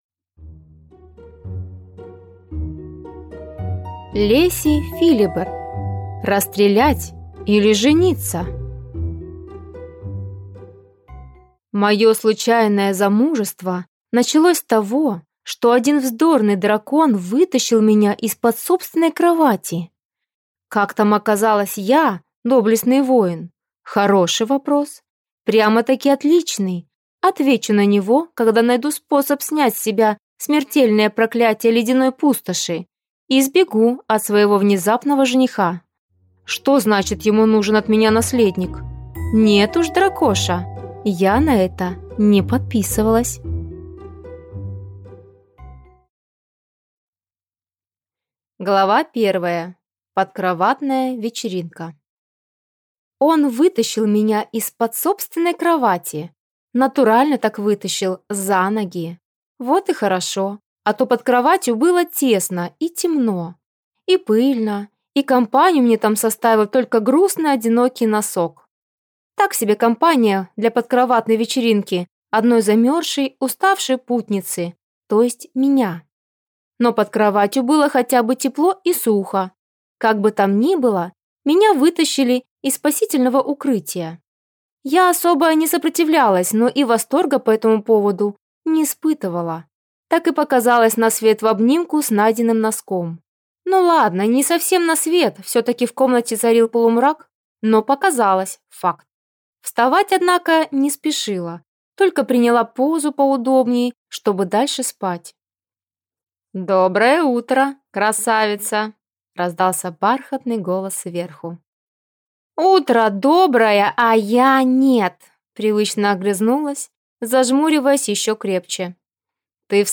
Аудиокнига Расстрелять или жениться?
Прослушать и бесплатно скачать фрагмент аудиокниги